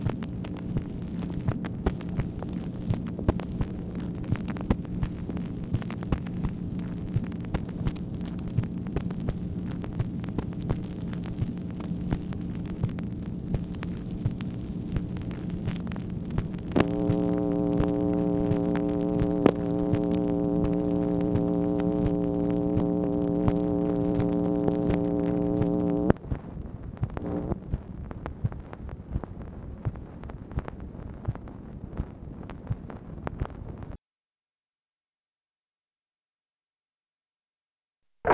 MACHINE NOISE, August 7, 1964
Secret White House Tapes | Lyndon B. Johnson Presidency